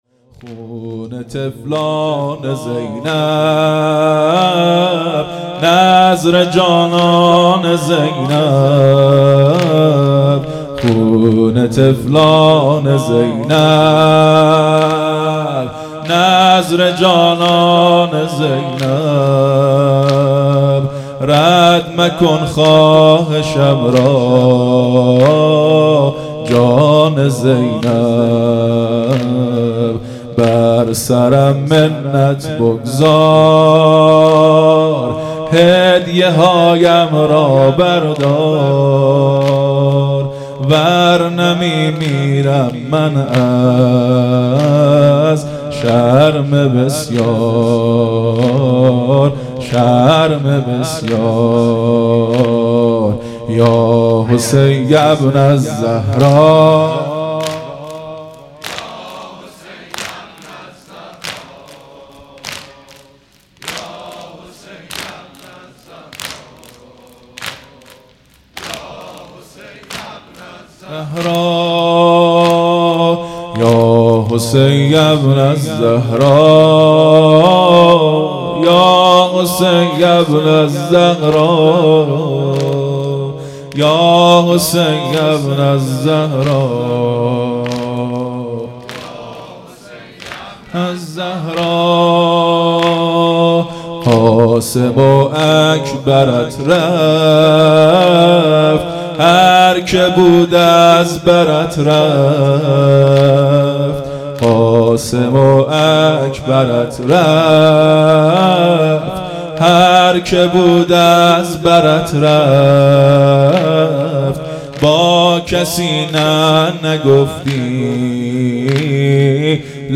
محرم1442_شب ششم